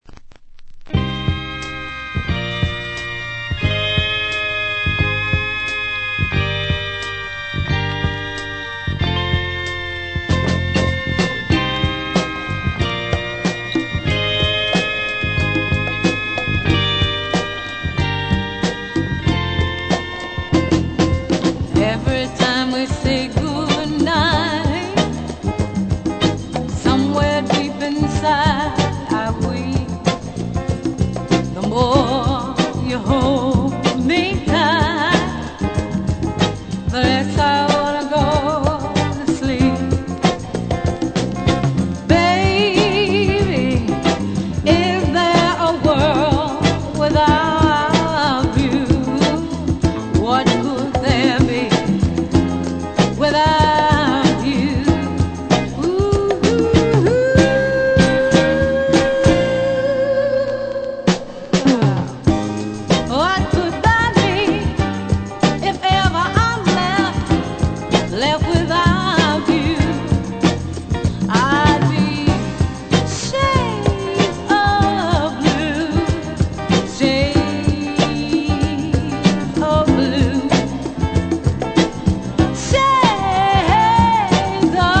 Genre: RARE SOUL